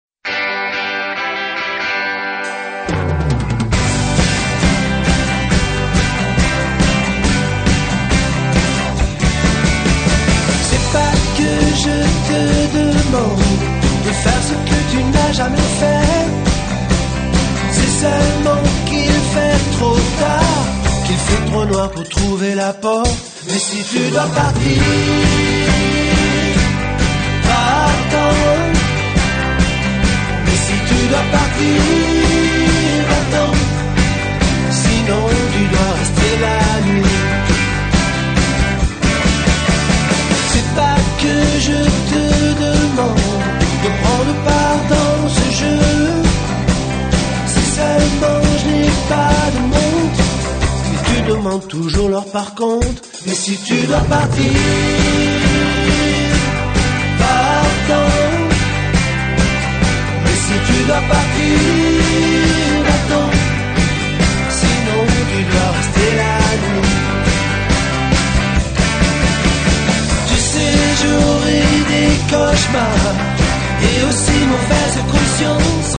19 titres de rock n’ roll Francais revisit?s